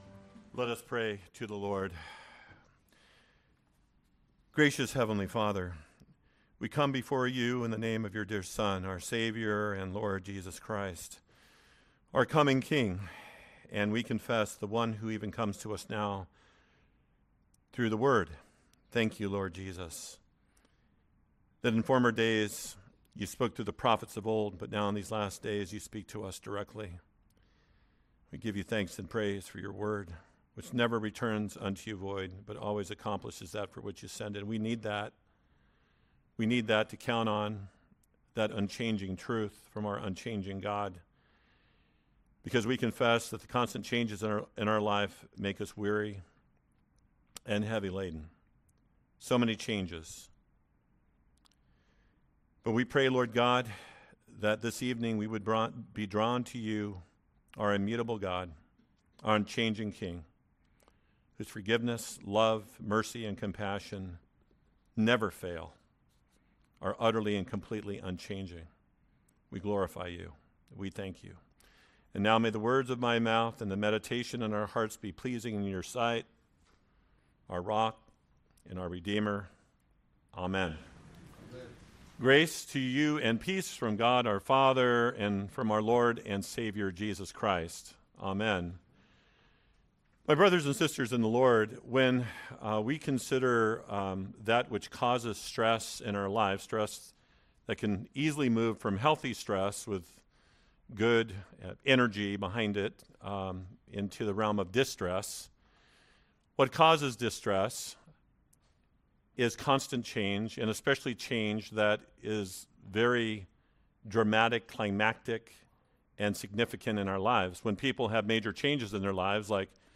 Wednesday Sermon